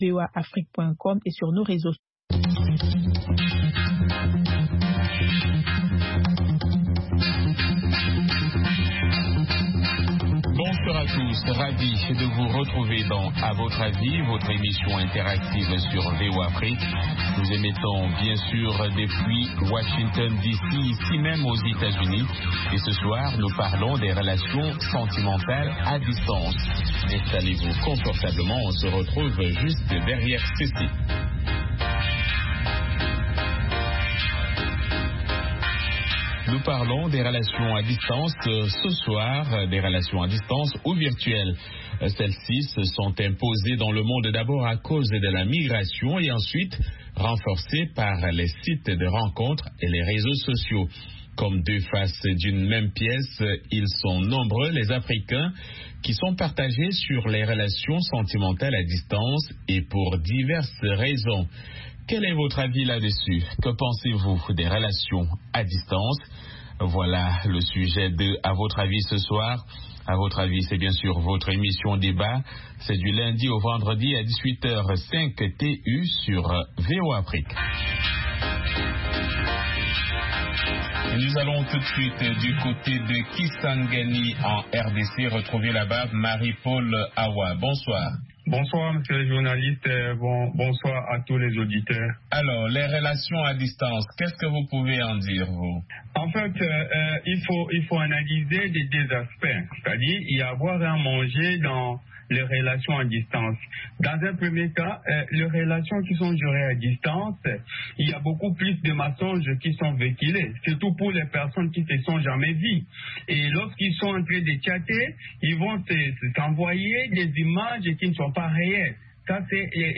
Le programme quotidien d'appel de VOA Afrique offre aux auditeurs un forum pour commenter et discuter d'un sujet donné, qu'il s'agisse d'actualités ou de grands sujets de débat.